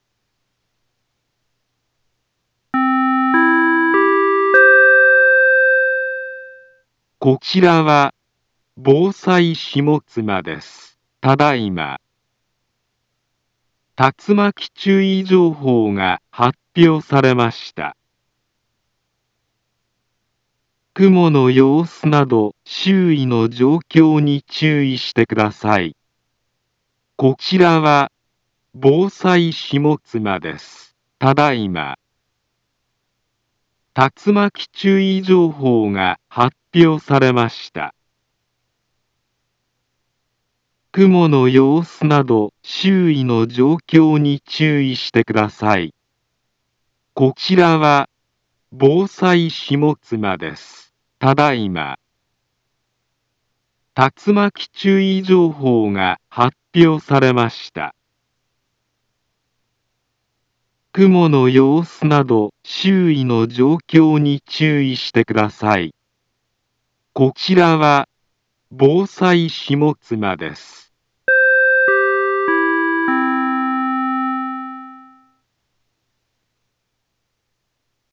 Back Home Ｊアラート情報 音声放送 再生 災害情報 カテゴリ：J-ALERT 登録日時：2025-07-01 15:34:36 インフォメーション：茨城県北部、南部は、竜巻などの激しい突風が発生しやすい気象状況になっています。